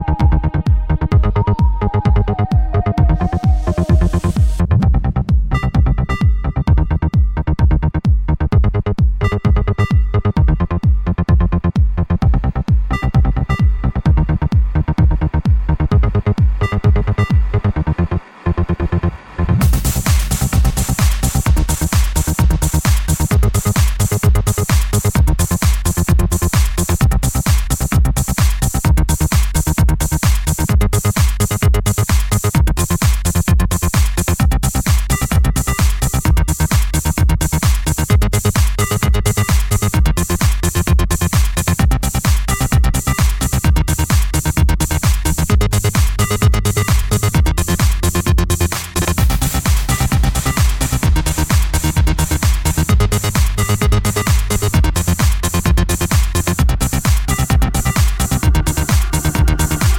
Электронная
главные house треки, сногсшибательную электронику